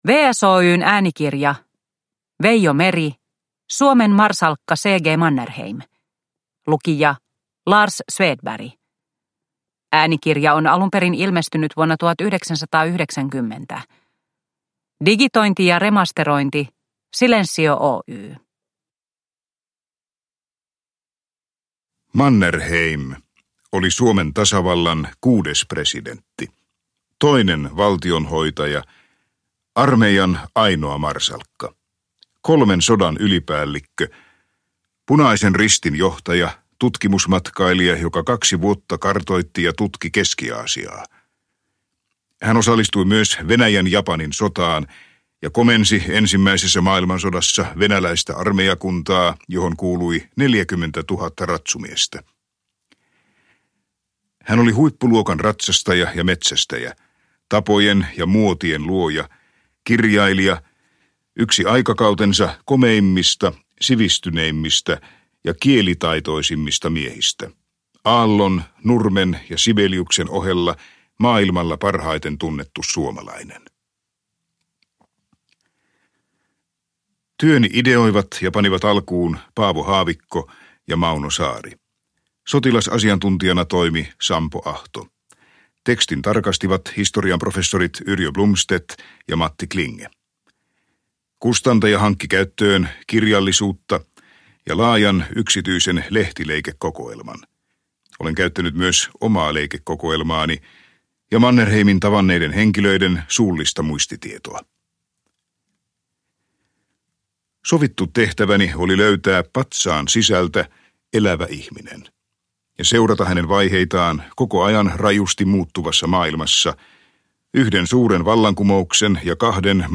Suomen marsalkka C. G. Mannerheim – Ljudbok – Laddas ner